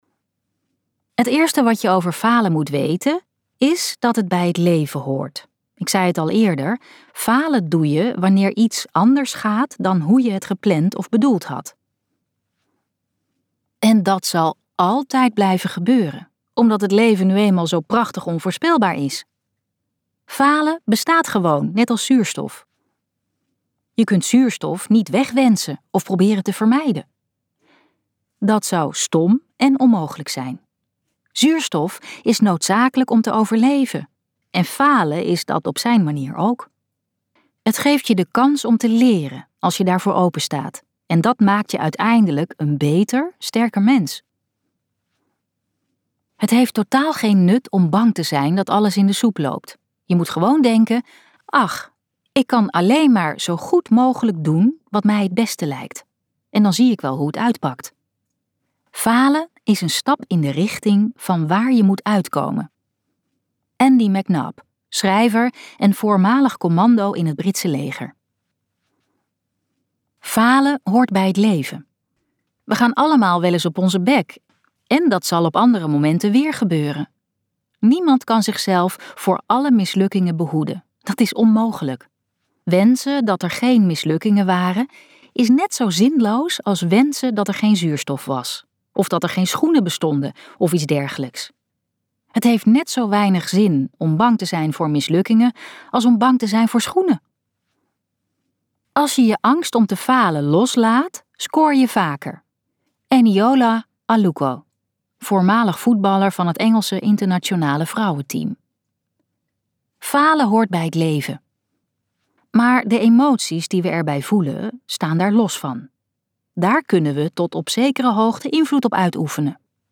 Ambo|Anthos uitgevers - Falen doen we allemaal luisterboek